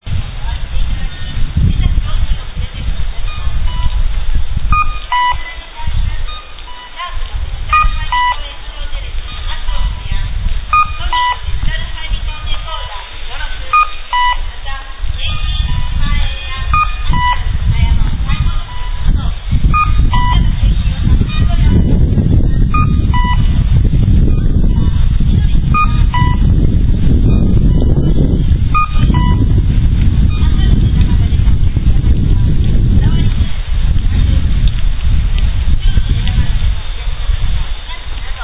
名古屋の音響式信号の音響装置は名古屋電機工業製がほとんどですが、この押しボタン式の音響式信号の音響装置は京三製作所製で、歩行者用信号青時の音は「かっこう」のみです。
このMP3ファイルは、この押しボタン式信号の歩行者用信号青時の「かっこう」です。鳴り方は、青信号時は鳴き交わし、青点滅時は鳴りません。